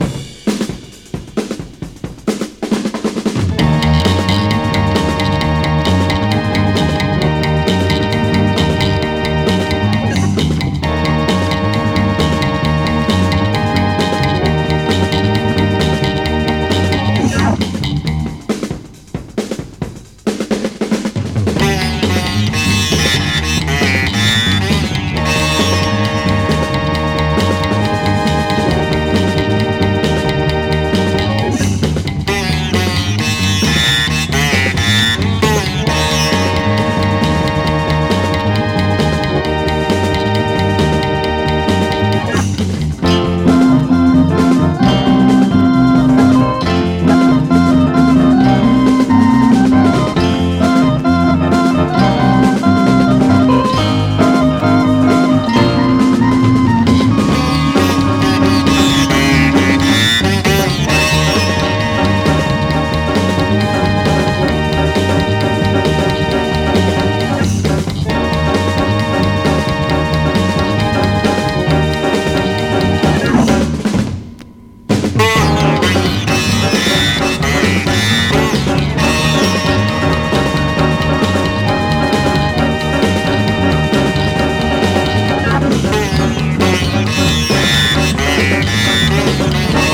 SOUL / RARE GROOVE/FUNK / MOOG / ORGAN
ハモンド・オルガン/MOOGによるグルーヴィー・カヴァー！